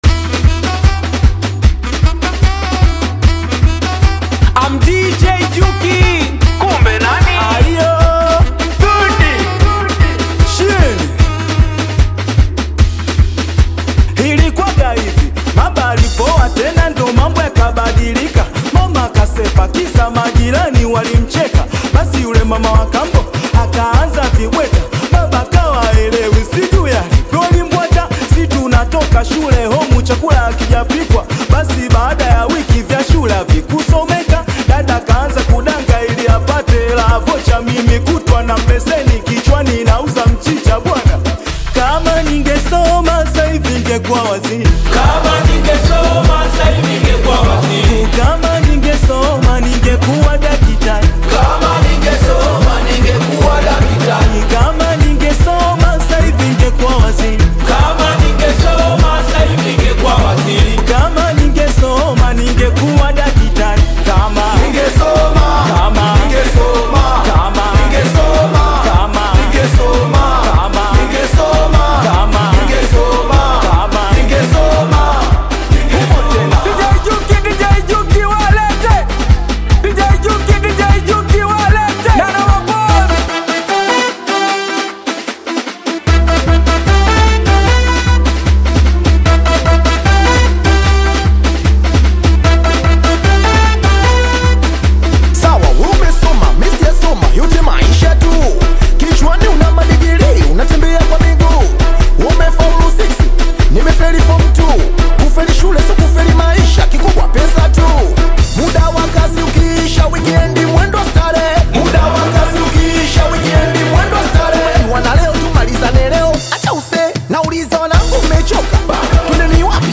Singeli music track